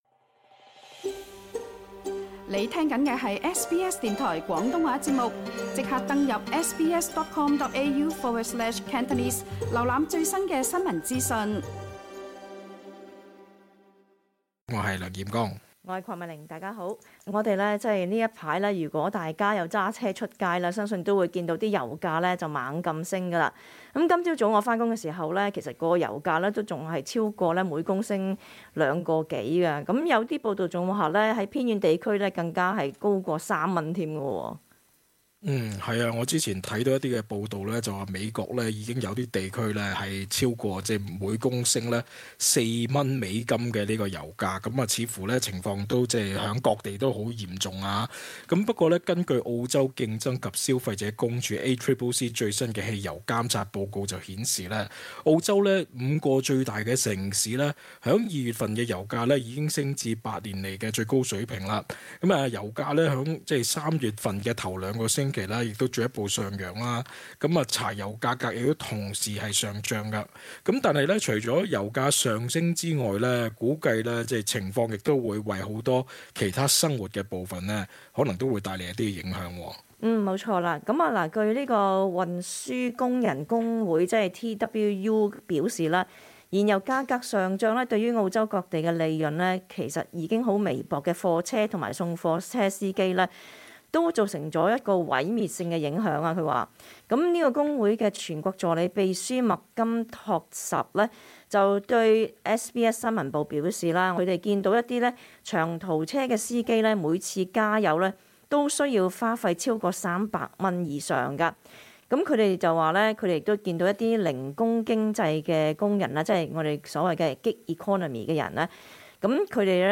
cantonese-_talkback_-_march_17-_final.mp3